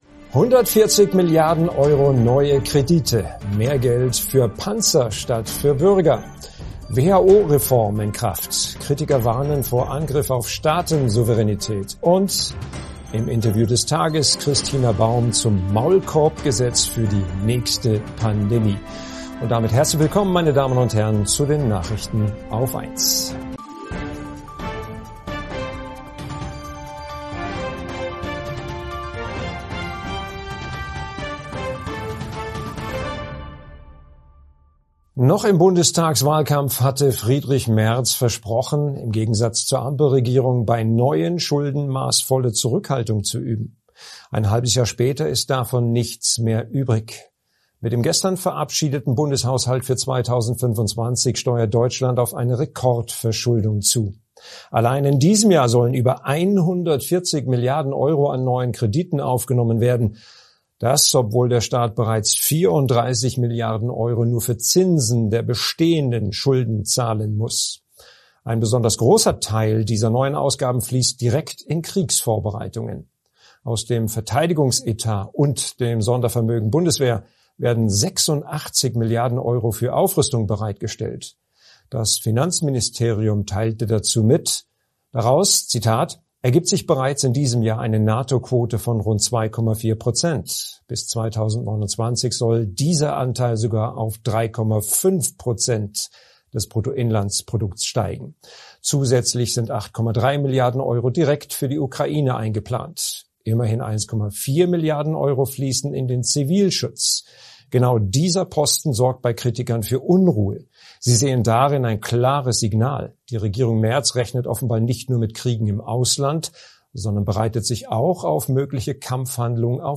140 Milliarden Euro neue Kredite: Mehr Geld für Panzer statt für Bürger + WHO-Reform in Kraft: Kritiker warnen vor Angriff auf Staaten-Souveränität + Und: Im Interview des Tages: Christina Baum zum Maulkorb-Gesetz für die nächste Pandemie